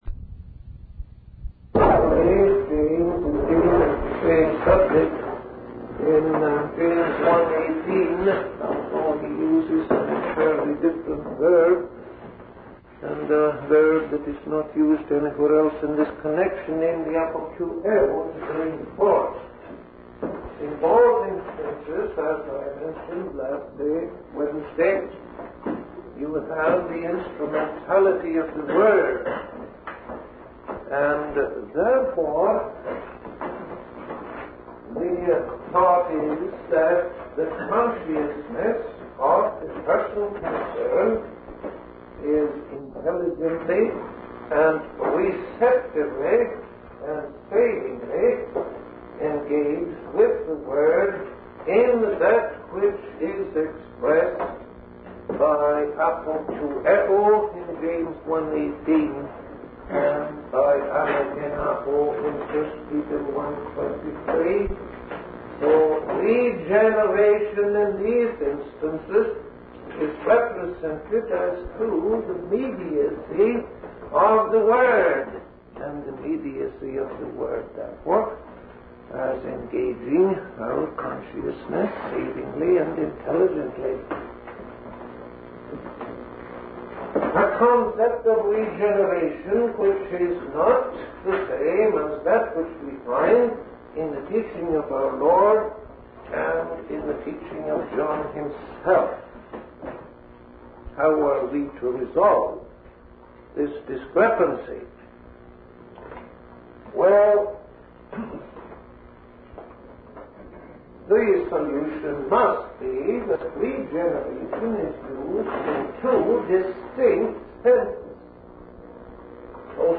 In this sermon, the speaker discusses the relationship between regeneration and responsibility in the context of the preaching of the word of God.